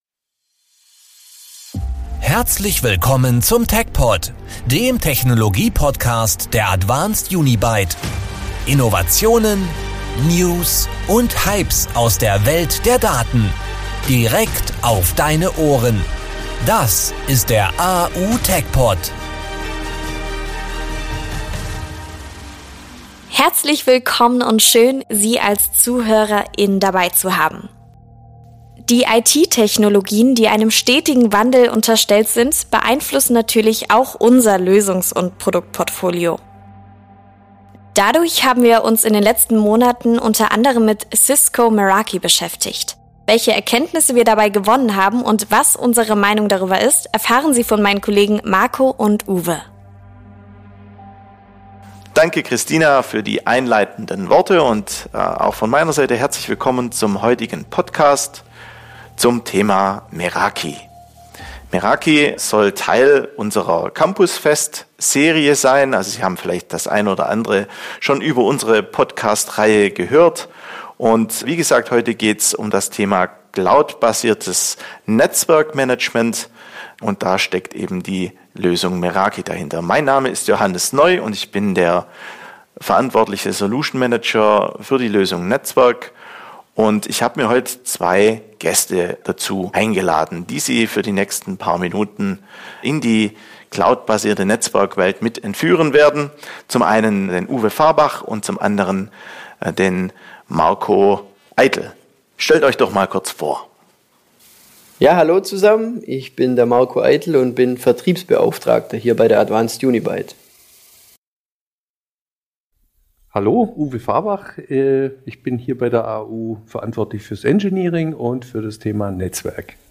Im Gespräch geht es um die typischen Anwendungsszenarien und Mehrwerte von Meraki.